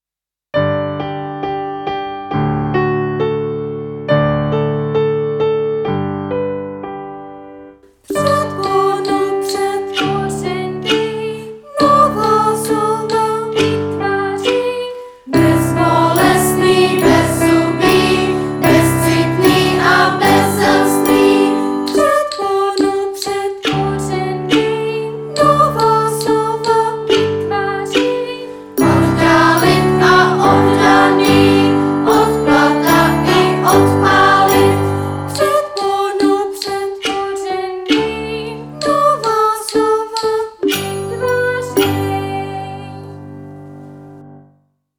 04. b_Tvoreni slov pribuznych pomoci predpon - pisen.mp3